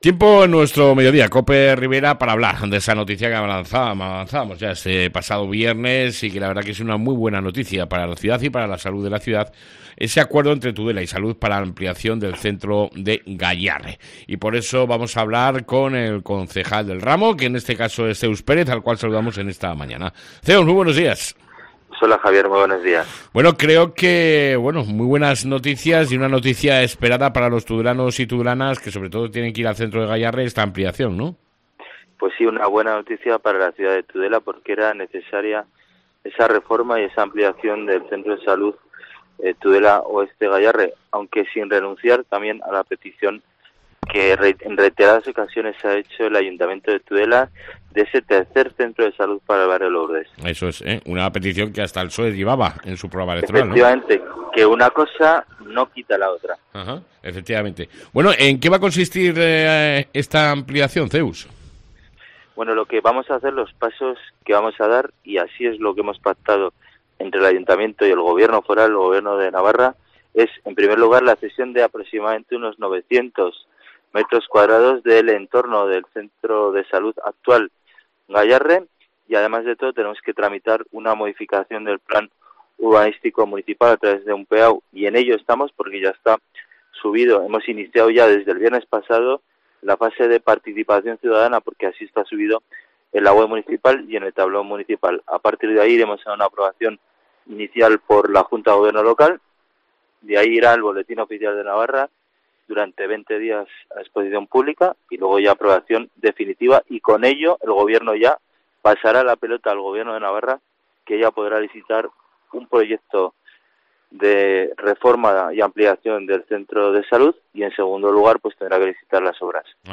ENTREVISTA CON EL CONCEJAL ZEUS PÉREZ